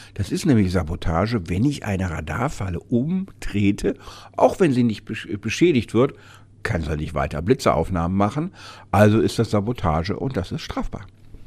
O-Ton: Umgestoßener Blitzer kann Straftat sein und teuer werden – Vorabs Medienproduktion